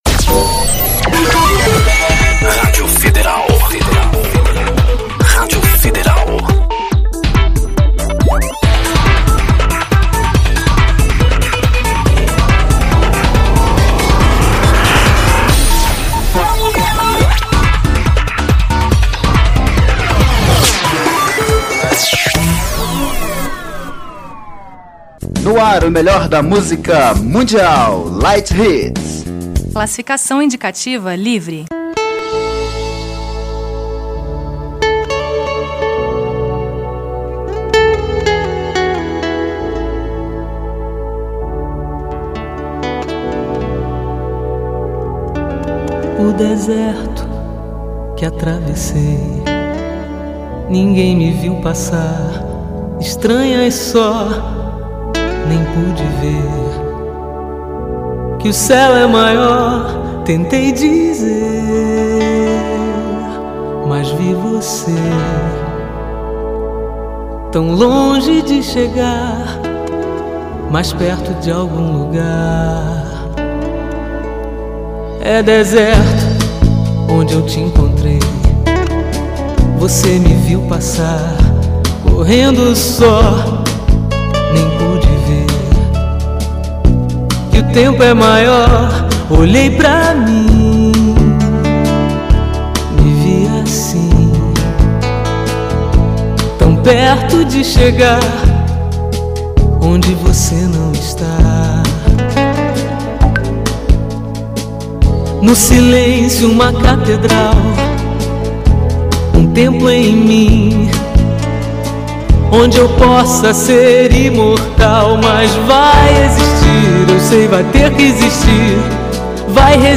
Uma viagem musical